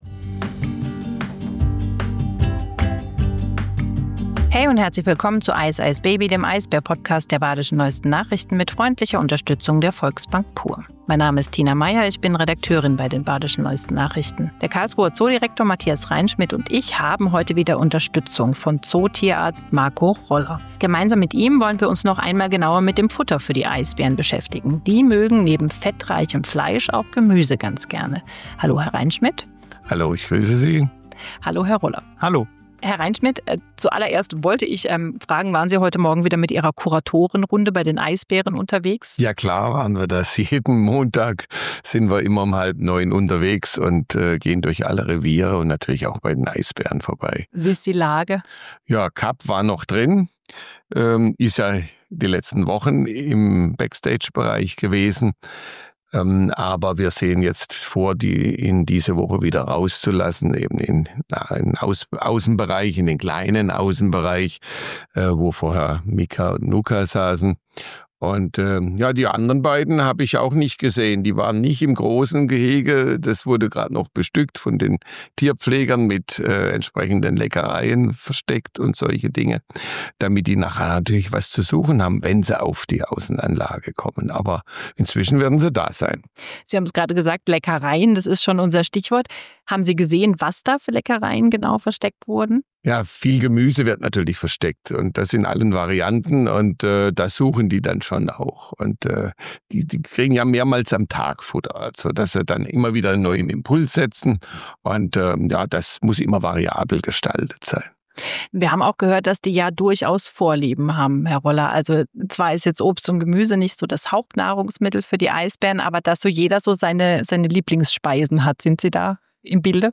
Dabei spielt auch eine Rolle, dass der Zoo Tiere tötet und verfüttert. Während der Aufzeichnung meldet sich übrigens auch der kleine Hyazinth-Papagei Nobby zu Wort – hungrig, versteht sich. Denn der hängt in der Voliere direkt neben dem Büro des Zoodirektors und hört alles mit.